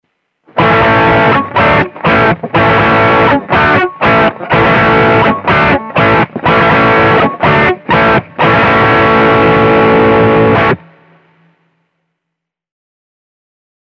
The active pickup
This is what an EMG 81 sounds like:
Schecter drive
schecter-drive.mp3